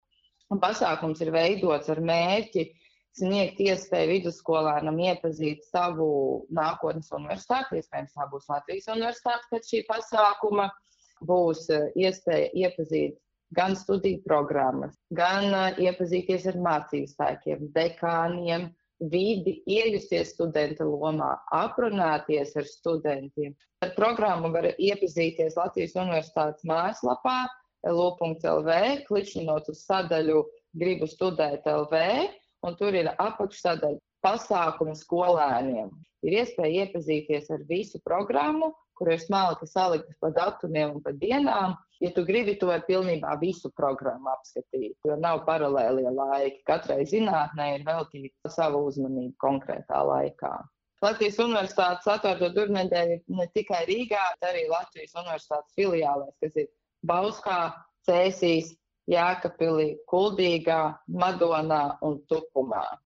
RADIO SKONTO Ziņās par LU “Atvērto durvju nedēļu”